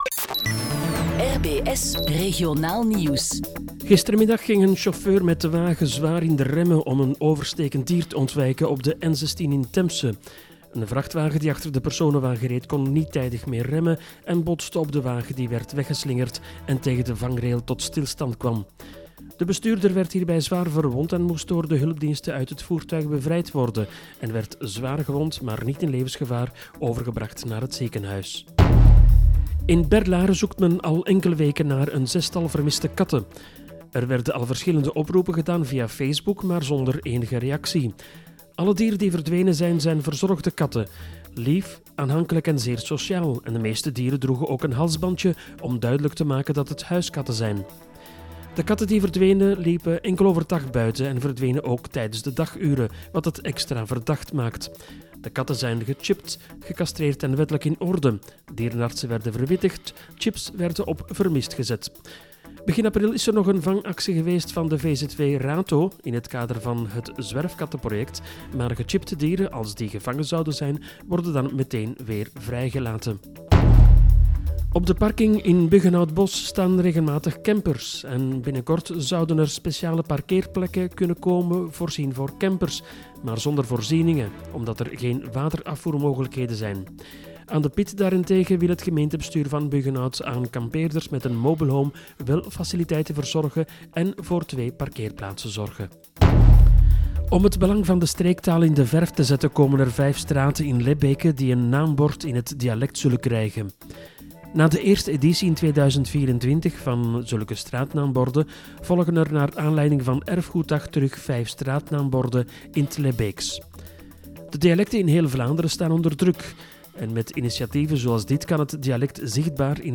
Regionaal Nieuws
Dit bericht wordt door een eigen webredactie gemaakt en ingelezen.